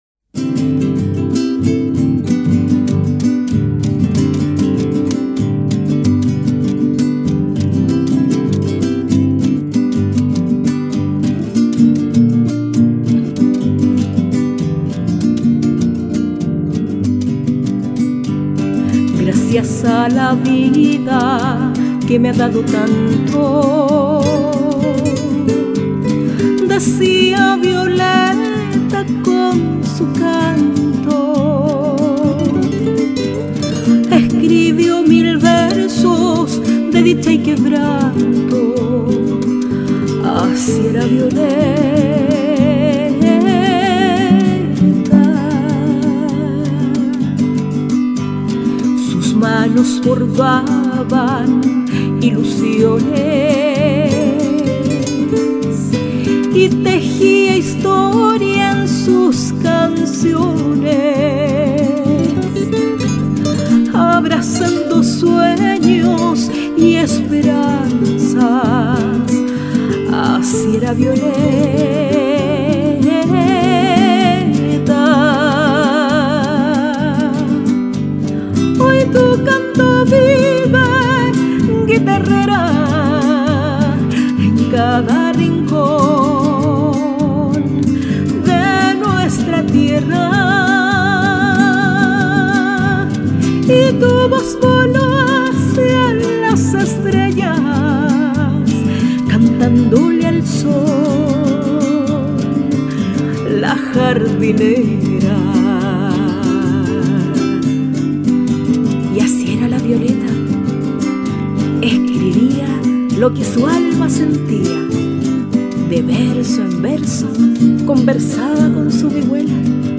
quien interpretará la tonada “Así era Violeta”